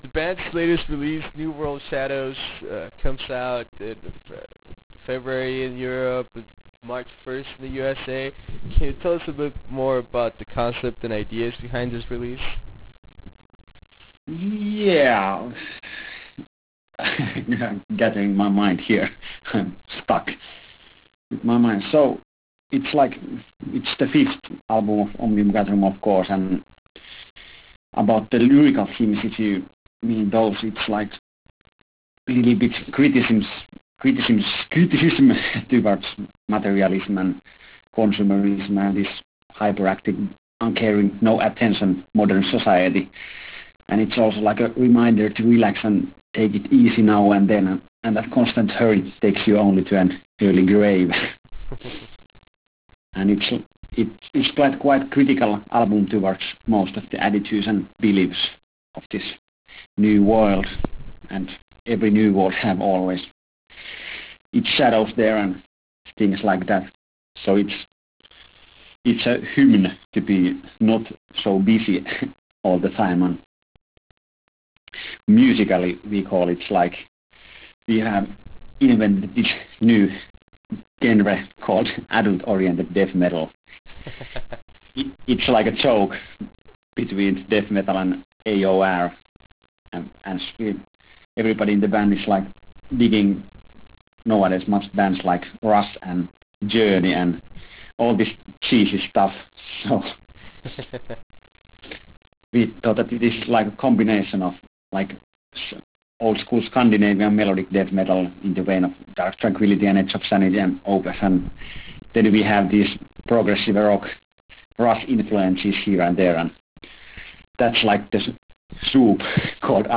Interview with Omnium Gatherum